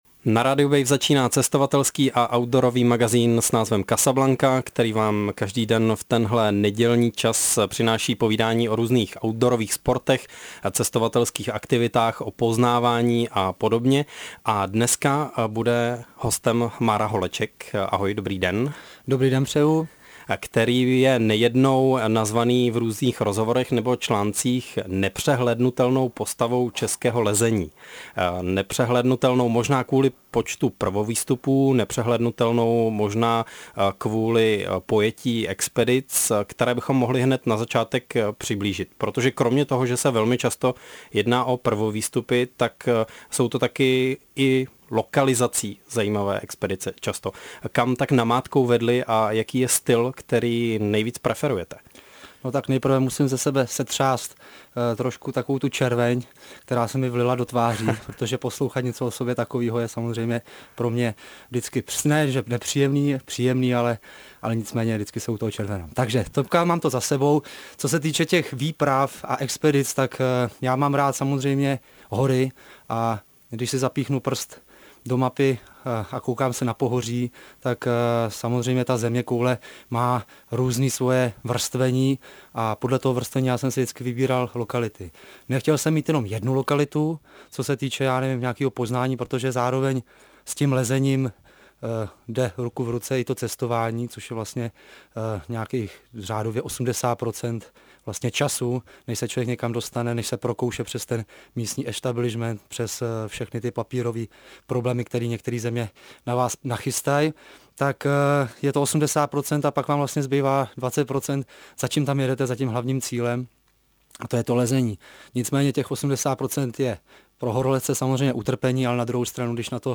Záznam povídání s Márou Holečkem Poslechněte si záznam pořadu, nebo si jej můžete stáhnout: Mára Holeček - Casablanca (42 minut, 32 MB)